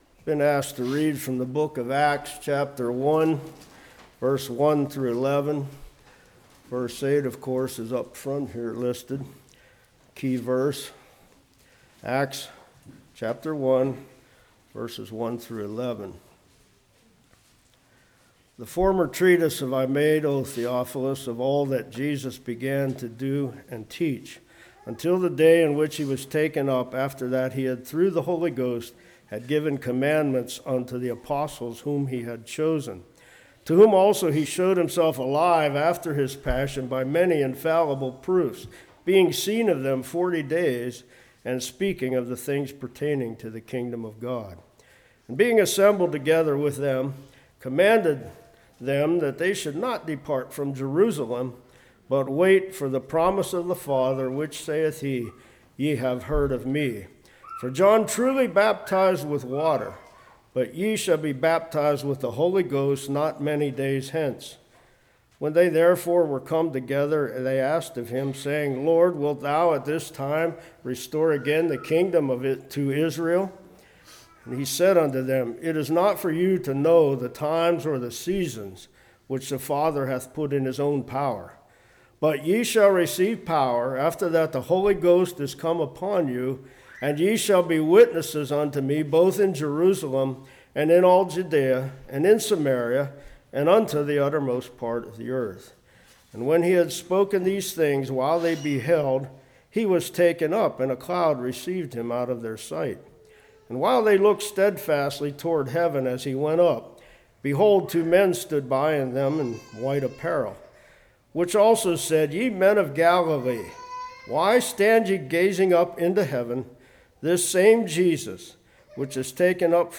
Acts 1:1-11 Service Type: Morning Who are you?